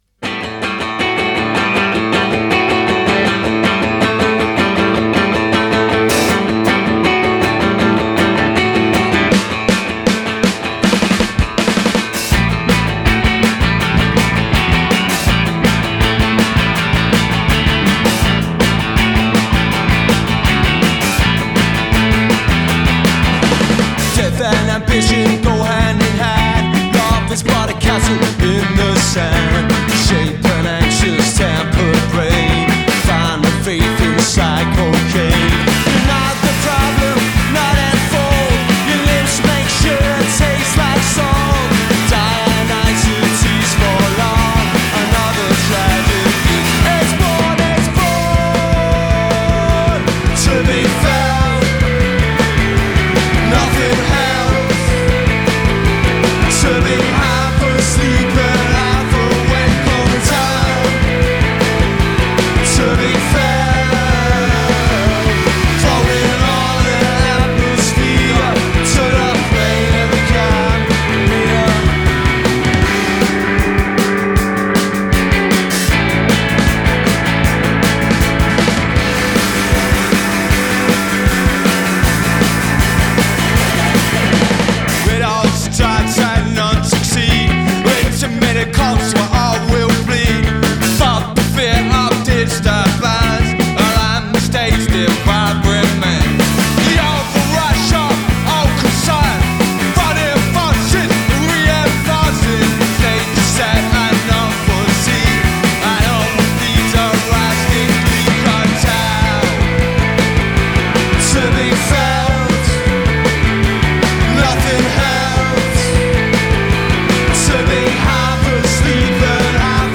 recorded and broadcast live
recorded live
A Psychedelic Punk Rock Racket.
both on guitar and vocals
bass
drummer